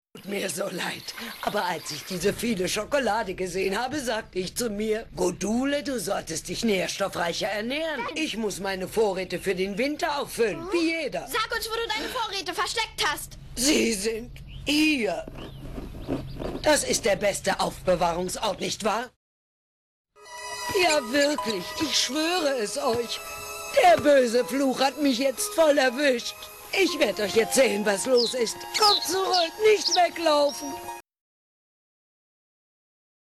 Synchronschauspielerin
Hier ein paar SPRACHPROBEN